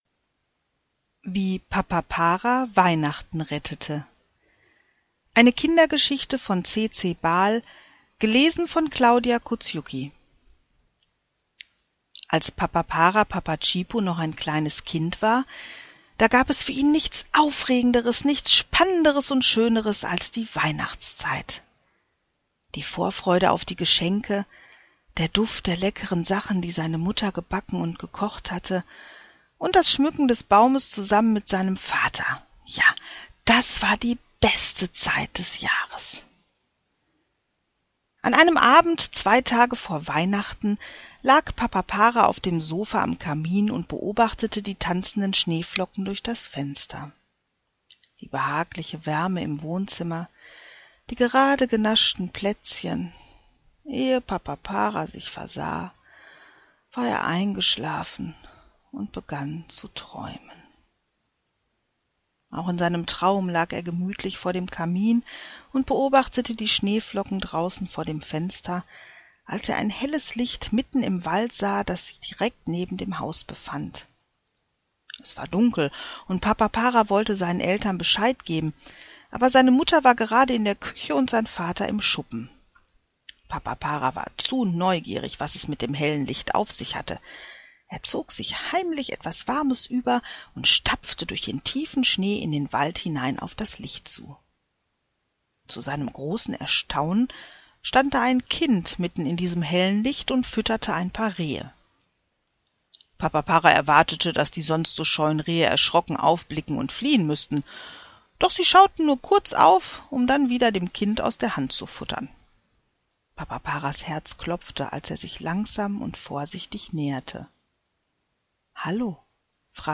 Sprecherin